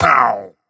Minecraft Version Minecraft Version latest Latest Release | Latest Snapshot latest / assets / minecraft / sounds / mob / pillager / hurt3.ogg Compare With Compare With Latest Release | Latest Snapshot
hurt3.ogg